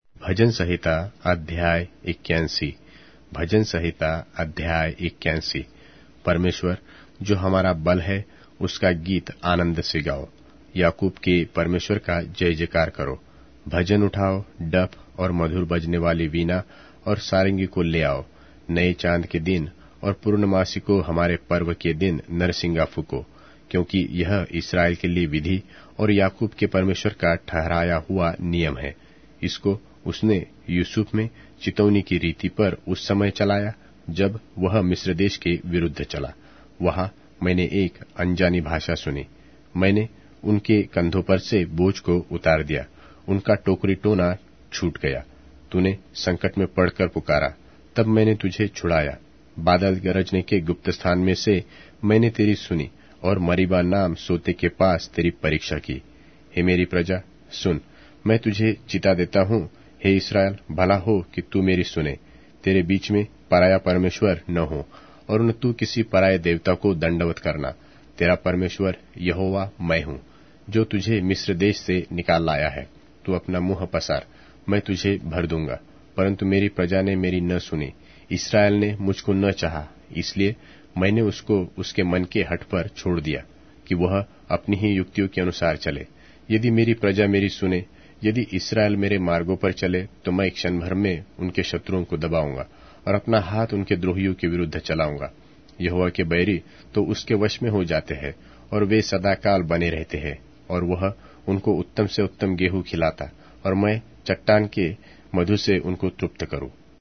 Hindi Audio Bible - Psalms 7 in Urv bible version